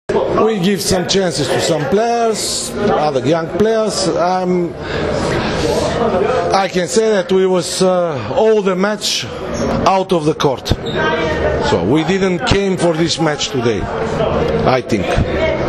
IZJAVA PLAMENA KONSTANTINOVA